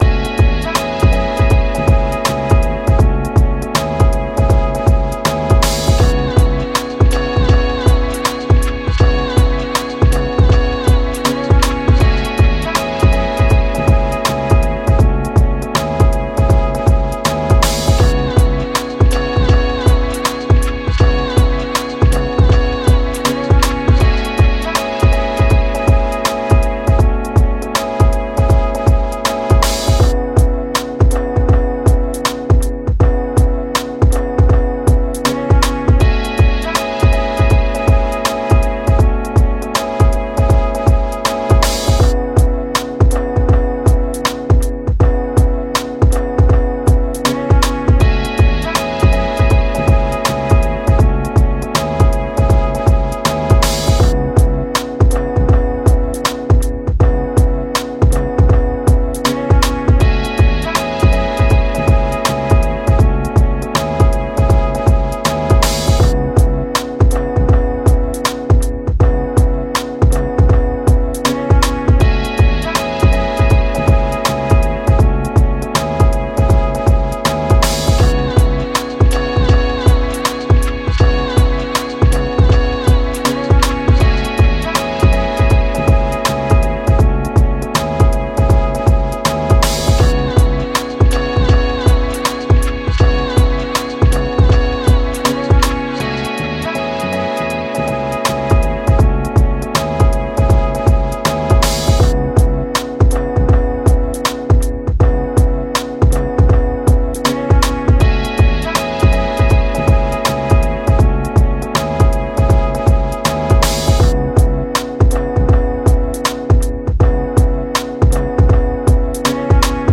Музыка для подкастов на радио